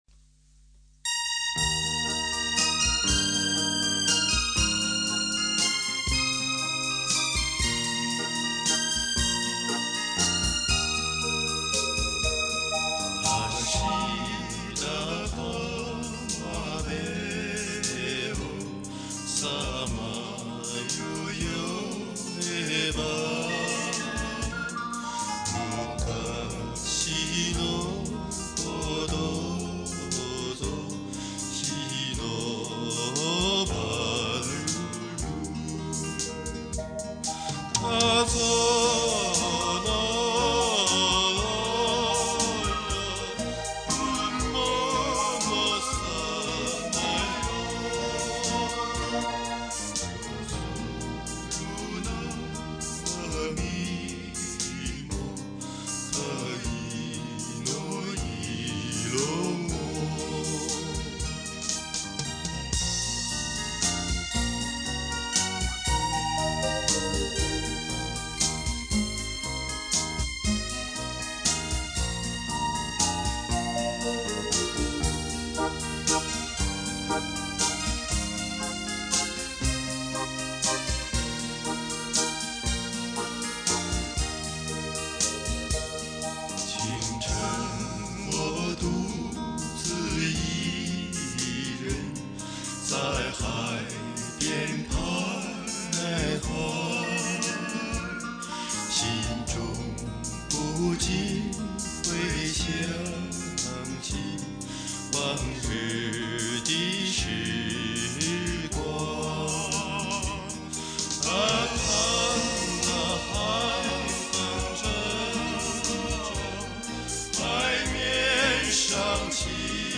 6/8拍舒缓的旋律构成了歌曲的A段。